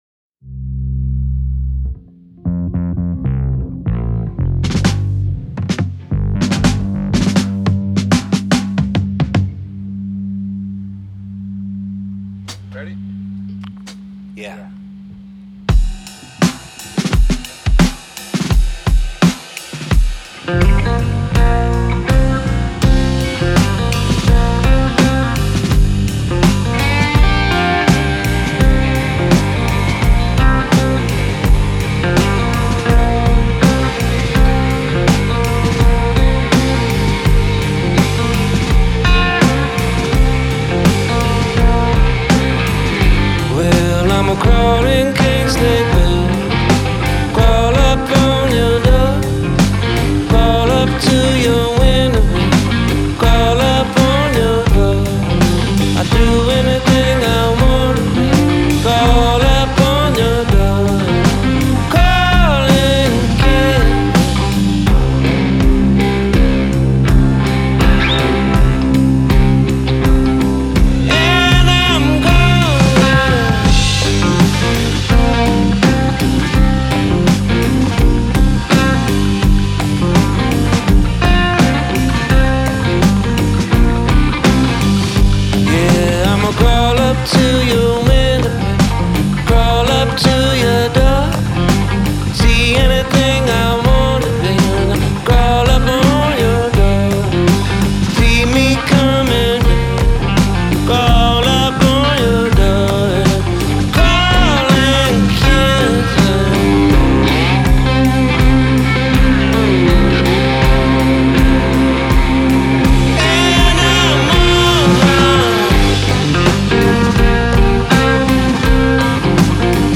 Genre : Blues, Rock